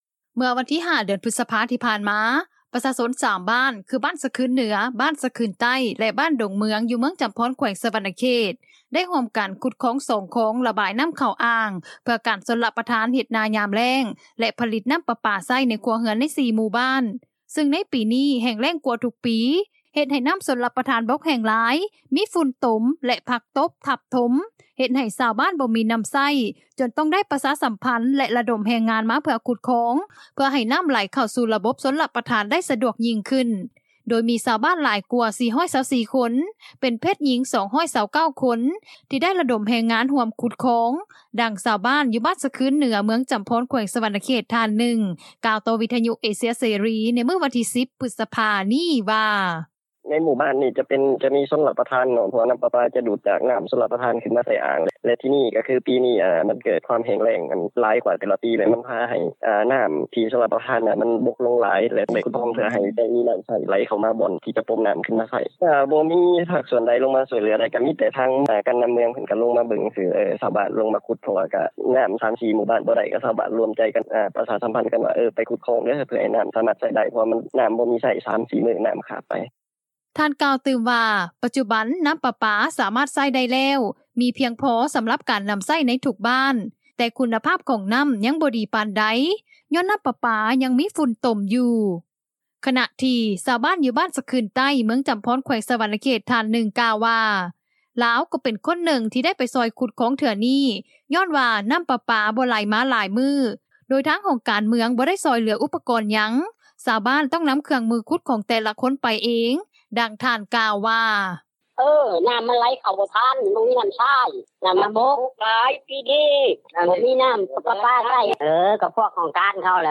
ດັ່ງຊາວບ້ານ ຢູ່ບ້ານຊະຄຶນເໜືອ ເມືອງຈໍາພອນ ແຂວງສະຫວັນນະເຂດ ທ່ານໜຶ່ງ ກ່າວຕໍ່ວິທຍຸເອເຊັຽເສຣີ ໃນມື້ວັນທີ 10 ພຶດສະພາ ນີ້ວ່າ:
ດ້ານເຈົ້າໜ້າທີ່ ລັດວິສາຫະກິດນໍ້າປະປາ ຢູ່ເມືອງຈໍາພອນ ແຂວງສະຫວັນນະເຂດ ທ່ານໜຶ່ງກ່າວວ່າ ໃນການຂຸດຄອງເທື່ອນີ້ ກໍ່ມີພາກສ່ວນທີ່ກ່ຽວຂ້ອງ ເປັນຕົ້ນ ຫ້ອງວ່າການປົກຄອງເມືອງ, ຫ້ອງການນໍ້າປະປາ ແລະຫ້ອງການ ກະສິກໍາແລະປ່າໄມ້ເມືອງ ໄດ້ລົງວຽກ ຊ່ອຍເຫຼືອຊາວບ້ານຄືກັນ ໂດຍນໍາທຶນມາລ້ຽງເຂົ້າ ແລະນໍ້າ ໃຫ້ປະຊາຊົນ, ດັ່ງທ່ານກ່າວວ່າ: